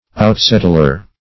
Outsettler \Out"set`tler\, n. One who settles at a distance, or away, from others.